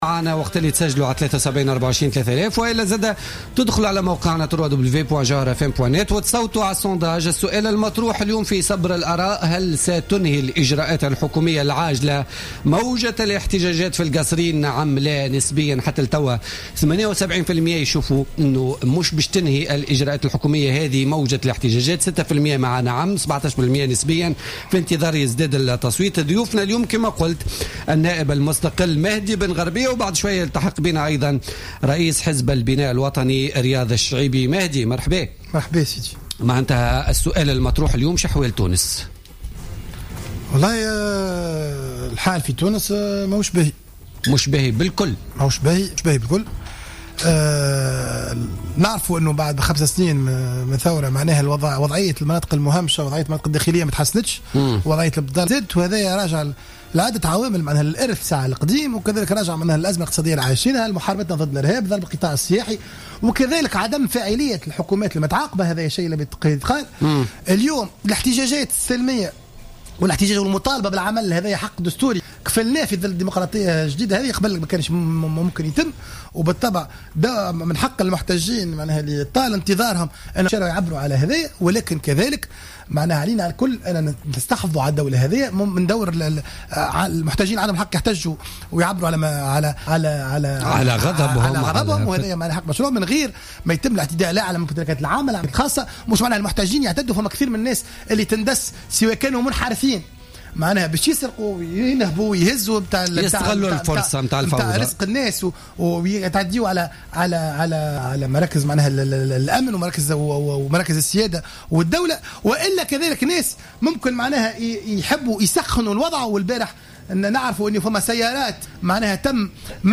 أكد النائب المستقل بمجلس نواب الشعب مهدي بن غربية ضيف بوليتيكا اليوم الخميس 21 جانفي 2016 أن وضع تونس اليوم ليس جيدا و رغم مرور 5سنوات على الثورة فإن وضعية المناطق المهمشة مازالت على ماهي عليه مرجعا ذلك للإرث القديم ولعدم فاعلية الحكومات المتعاقبة على تونس.